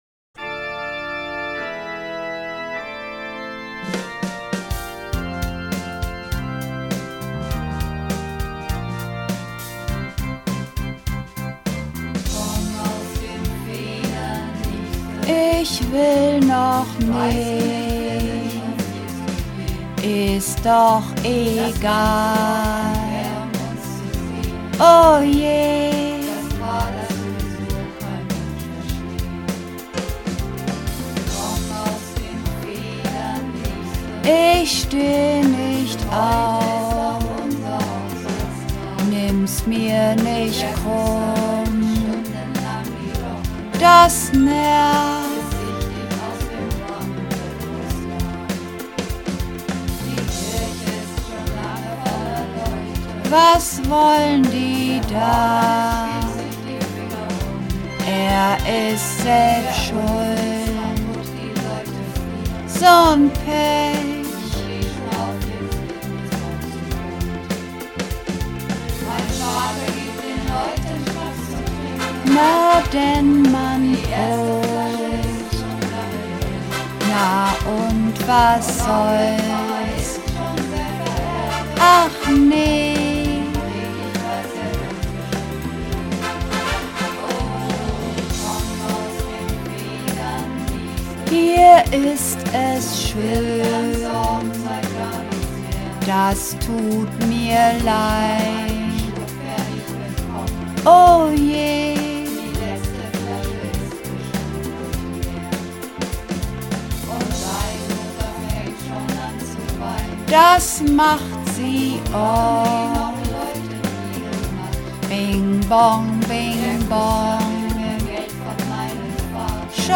Übungsaufnahmen - Komm aus den Federn, Liebste!
Komm_aus_den_Federn_Liebste__3_Sopran.mp3